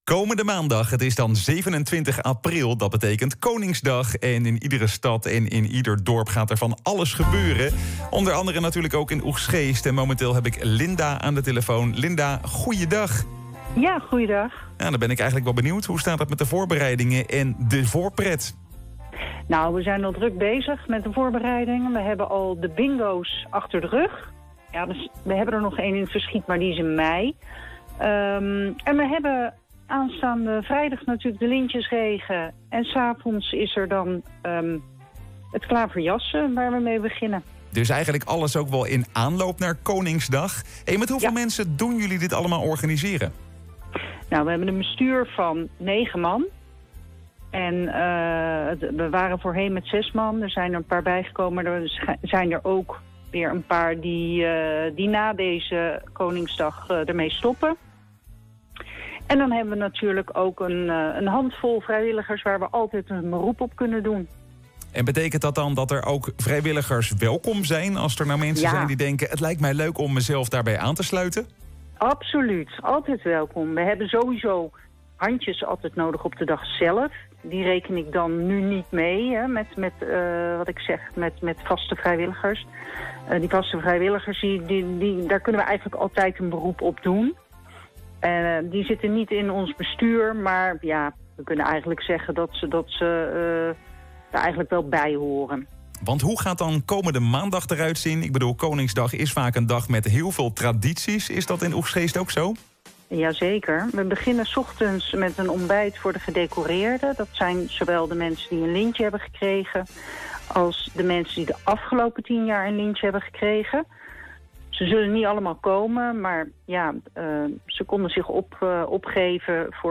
Interview Maatschappij Oegstgeest Gedecoreerden Koningsdag muziek oegstgeest Programma Tradities